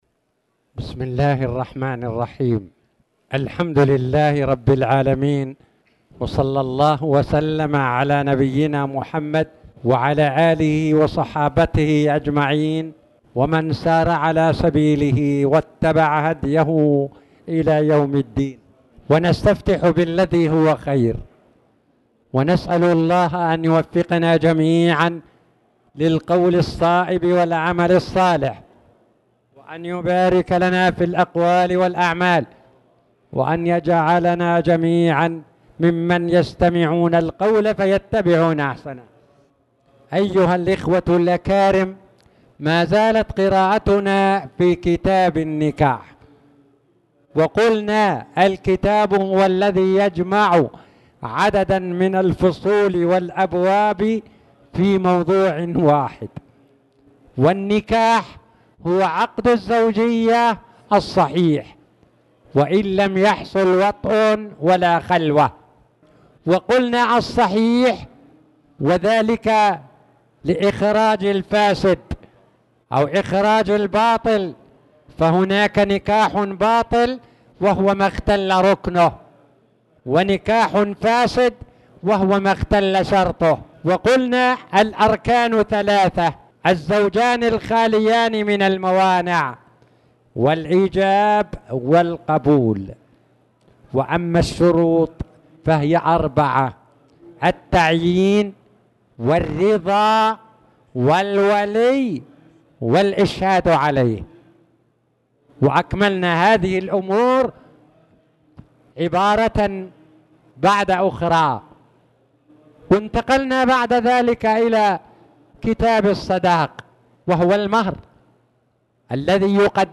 تاريخ النشر ٢٤ ربيع الثاني ١٤٣٨ هـ المكان: المسجد الحرام الشيخ